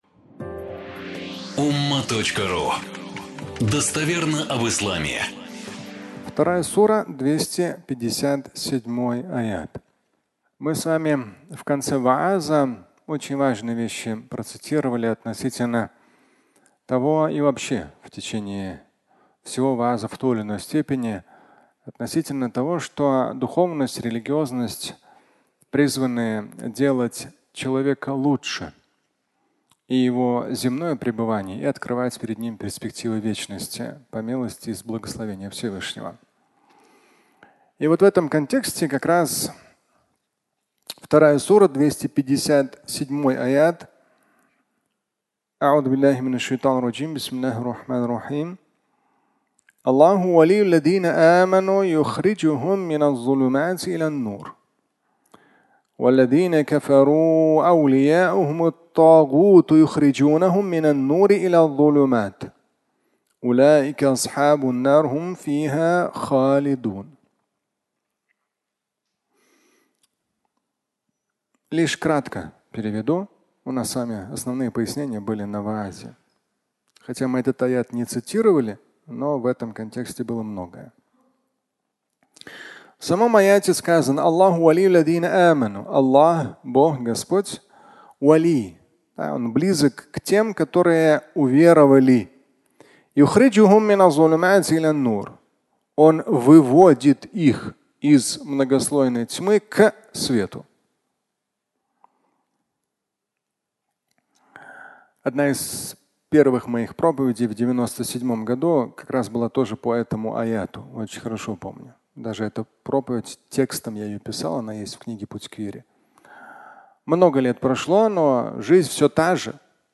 Фрагмент пятничной лекции, в котором Шамиль Аляутдинов цитирует Св. Коран, 2:257 и говорит о вере и неблагодарности.